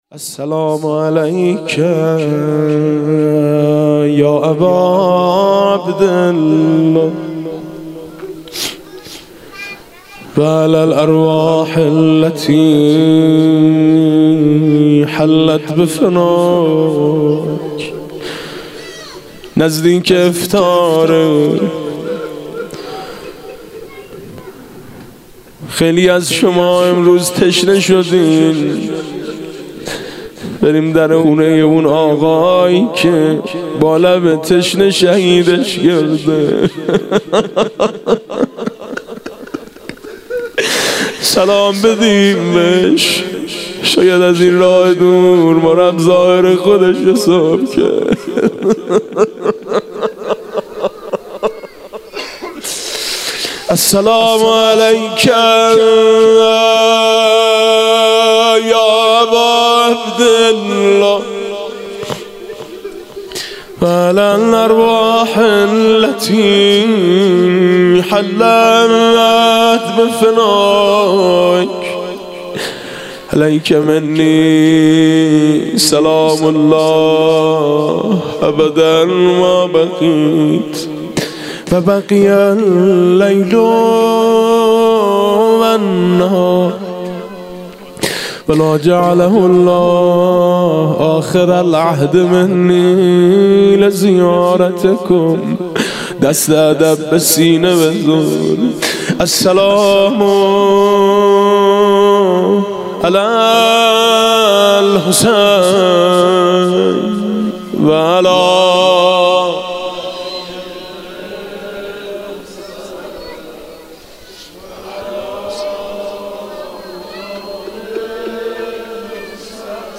27 رمضان 97 - هیئت میثاق با شهدا - روضه - نگاه کن منم سکینه دخترت، شناختی